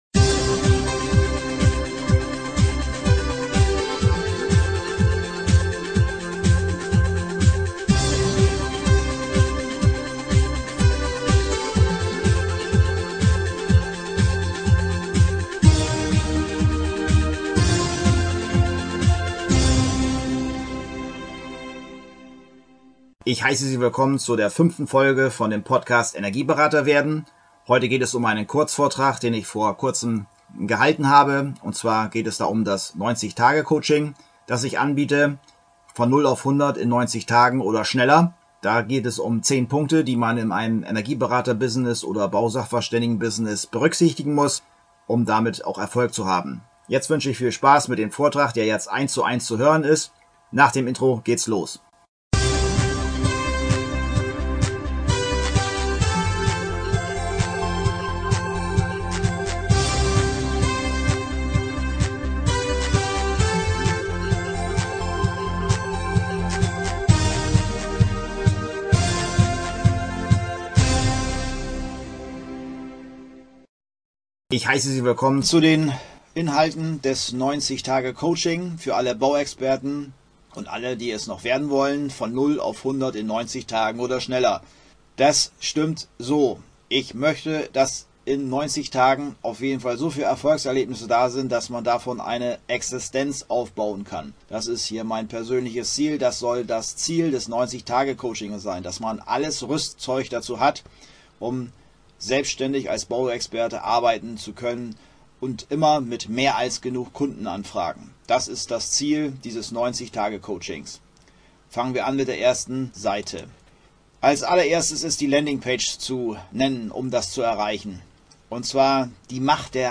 Folge #5: Diese Podcast-Folge beschäftig sich mit meinem Kurzvortrag, den ich vor Kurzem gehalten habe.